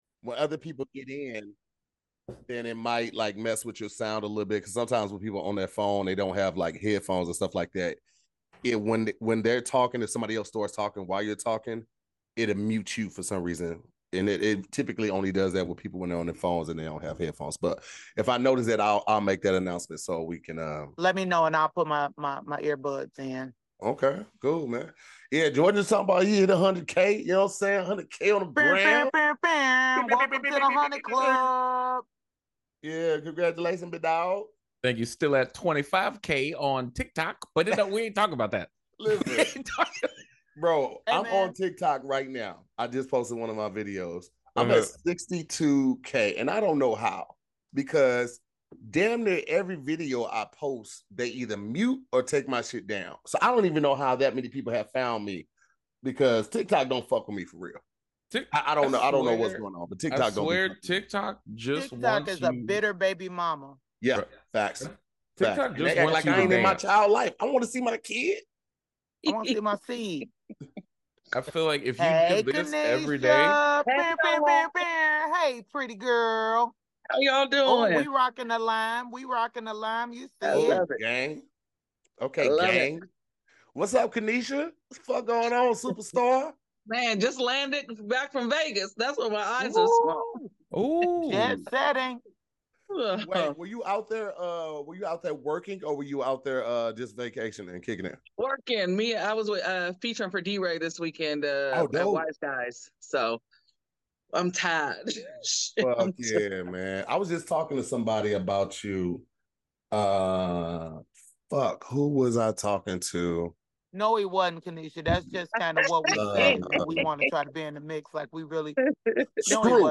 Each week, the SquADD will debate topics and vote at the end to see what wins.